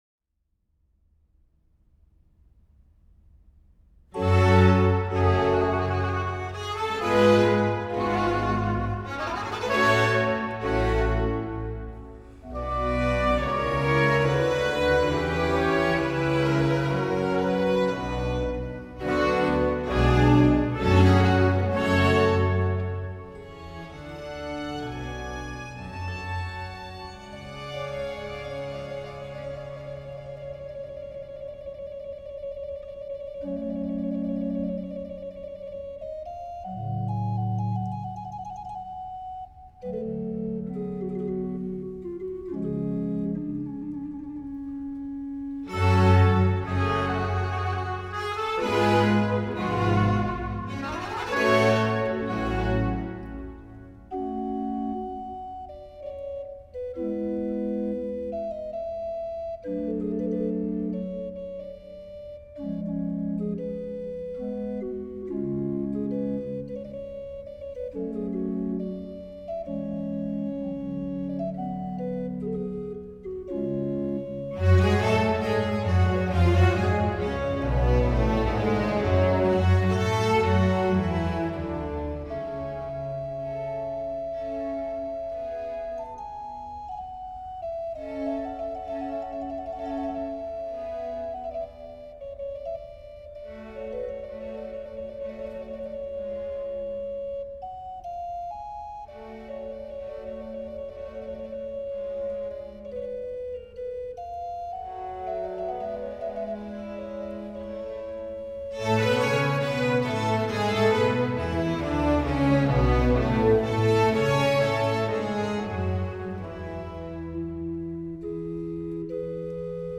Классическая Музыка
Organ Concerto in G minor